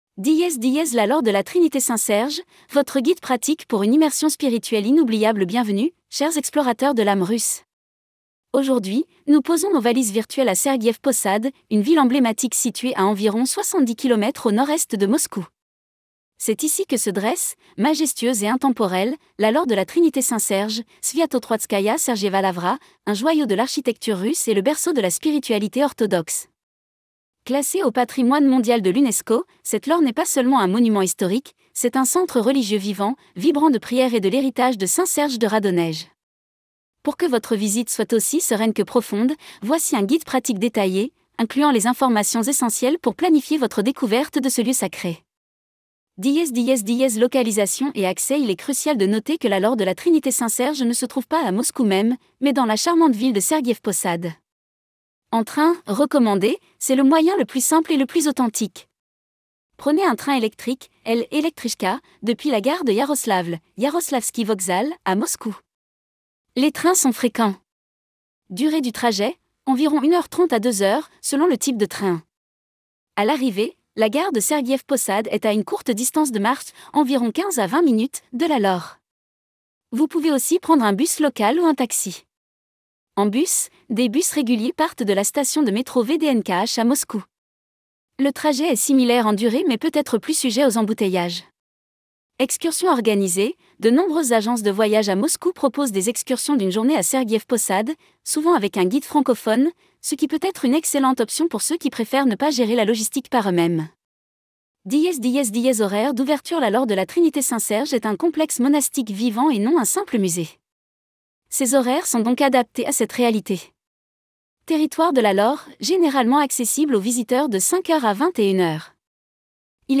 Lavra de la Trinité Sergiyev Posad - Audioguide Gratuit